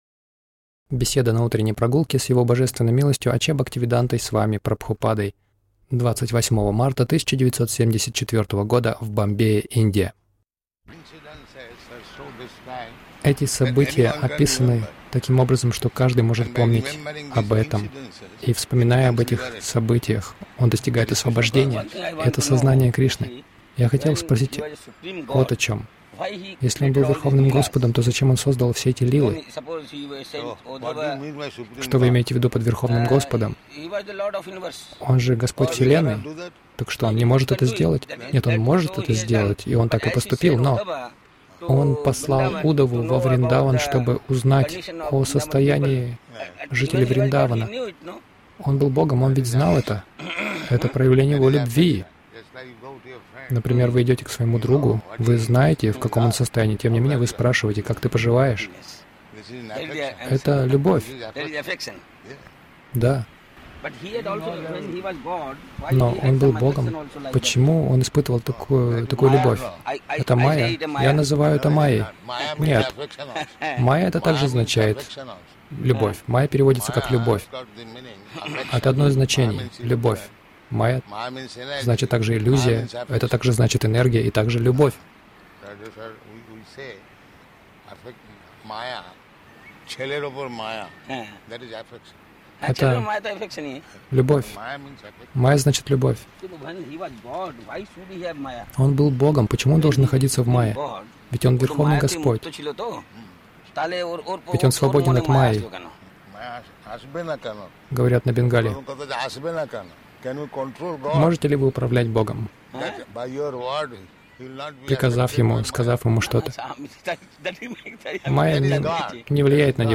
Милость Прабхупады Аудиолекции и книги 28.03.1974 Утренние Прогулки | Бомбей Утренние прогулки — Майа — иллюзия или любовь Загрузка...